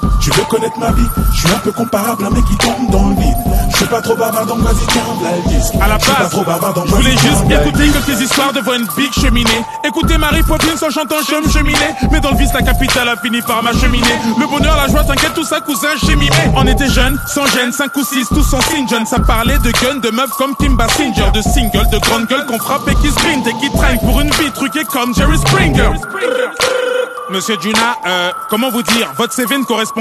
solo glacial